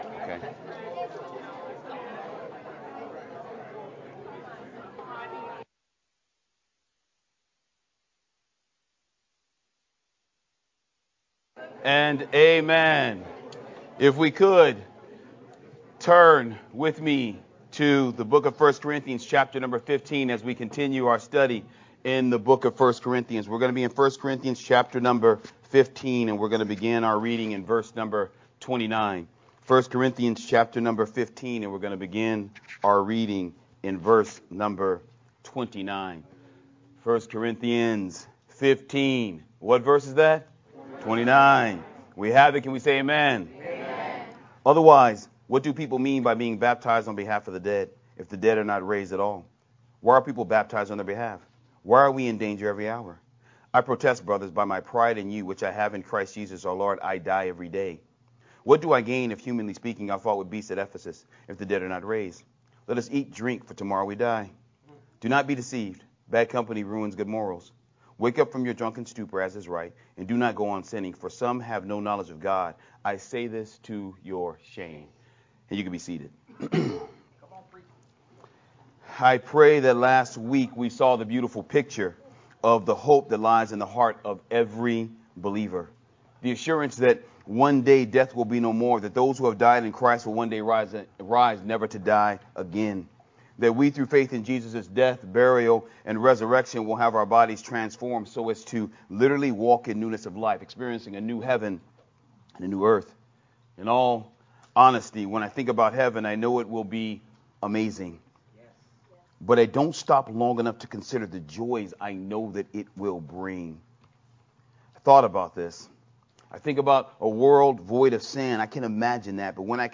Sermon From 1st Corinthians 15:29-34